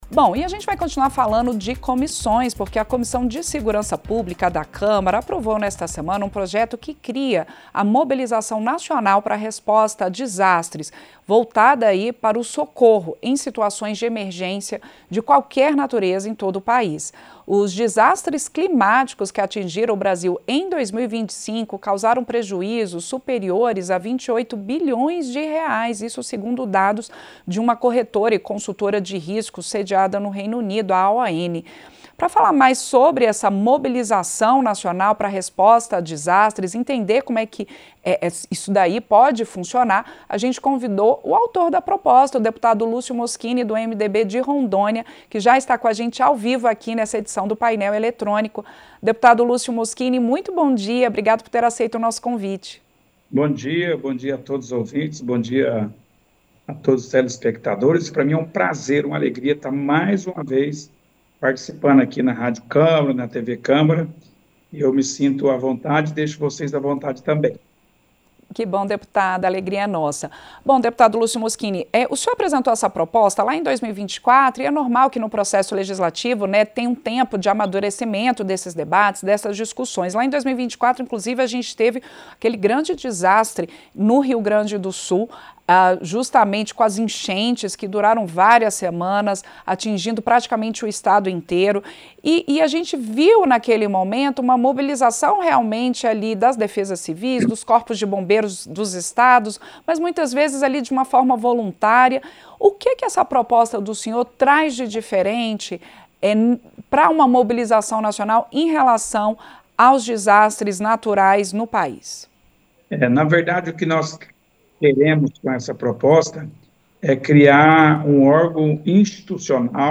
Entrevista - Dep Lúcio Mosquini (MDB-RO)